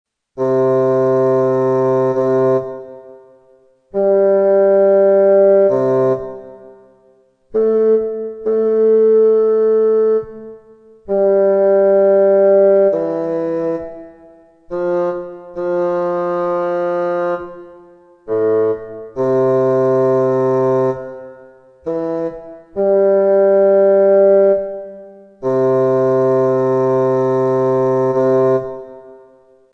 Ascolto selettivo
Ascolta queste 8 coppie di suoni.